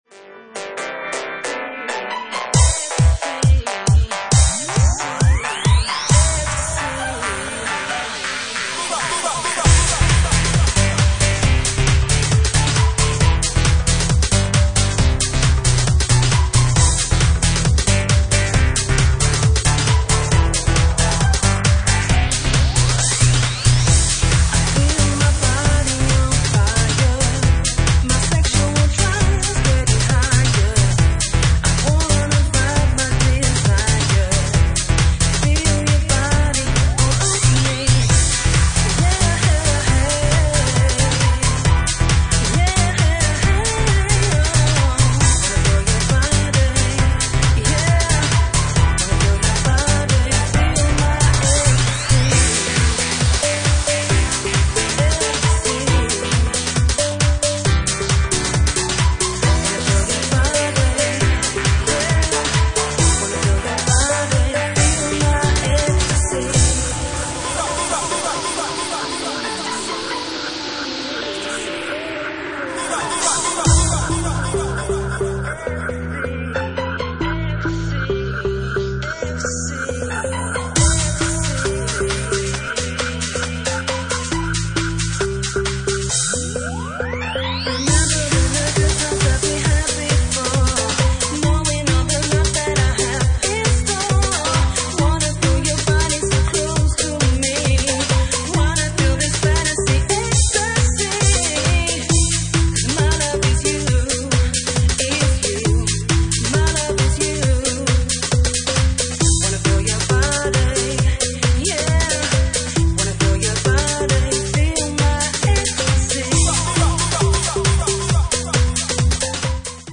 Genre:Bassline House
Bassline House at 135 bpm